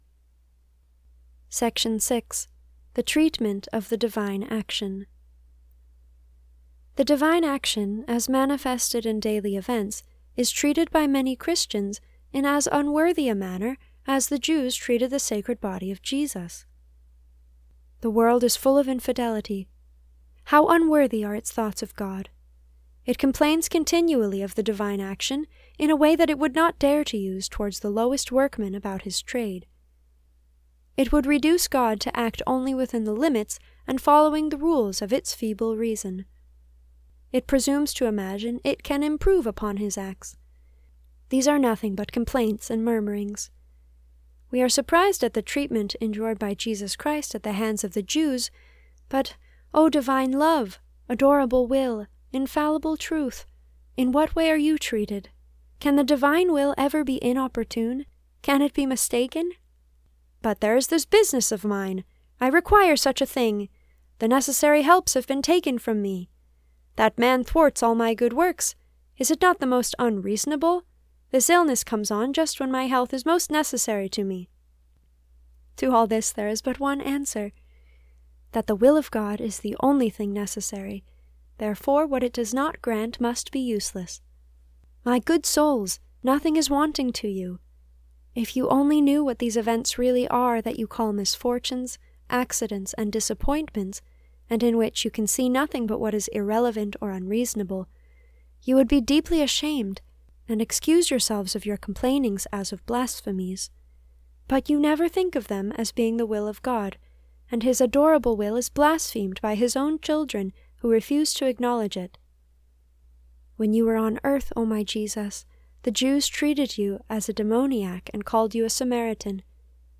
This is a reading from the spiritual classic Abandonment to Divine Providence by Jean Pierre de Caussade (1675 - 1751).